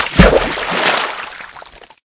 splash.ogg